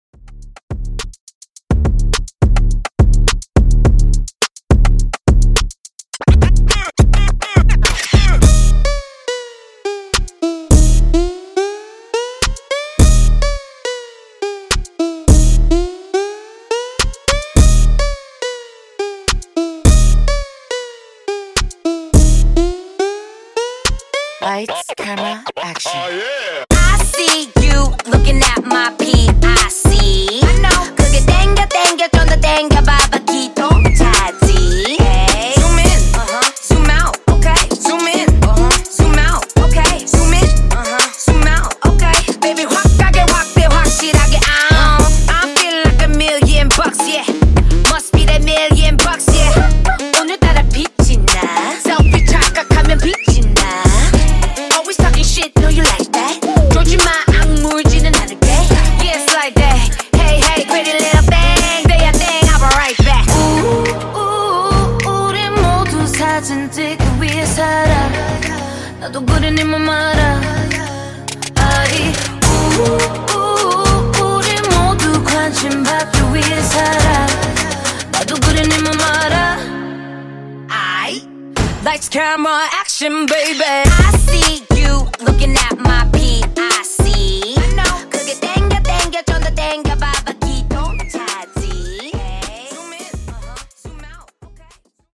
Tiktok Pop Redrum)Date Added